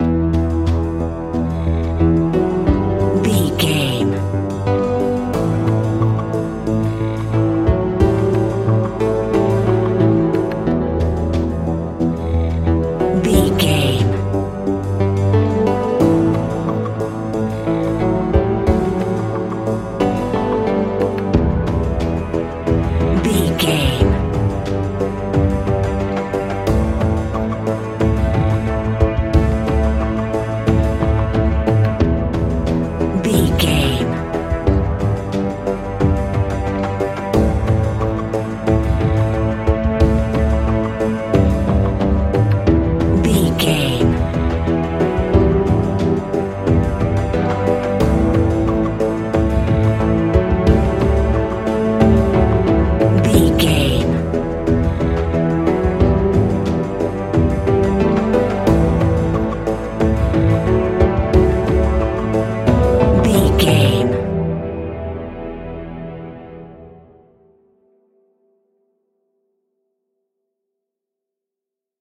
Aeolian/Minor
ominous
dark
haunting
eerie
percussion
synthesizer
mysterious
horror music
Horror Pads
horror piano
Horror Synths